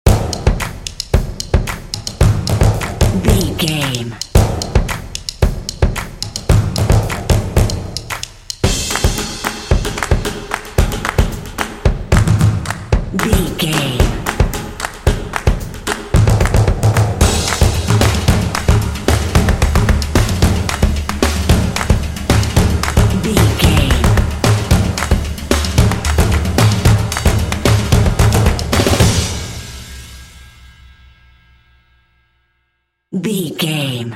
Epic / Action
Atonal
confident
determined
tension
drums
percussion
drumline